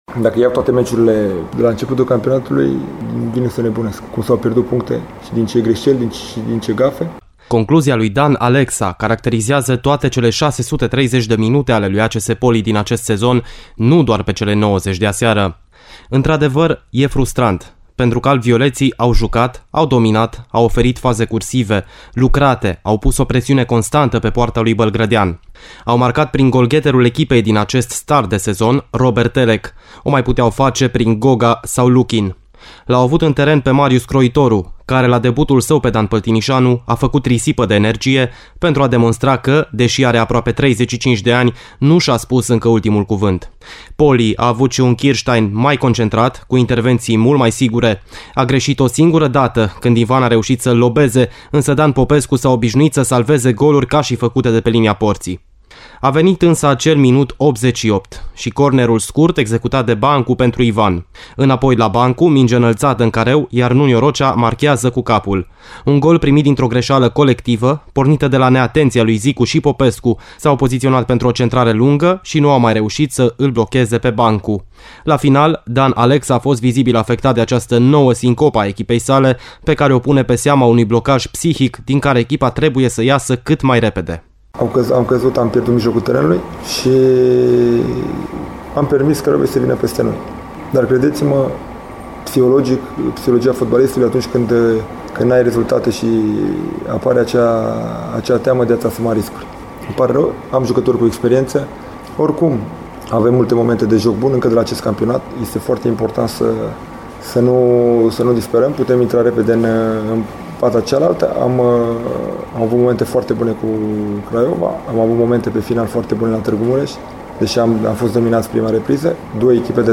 Reacţiile celor doi antrenori le puteţi asculta în reportajul realizat de